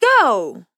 Voices Expressions Demo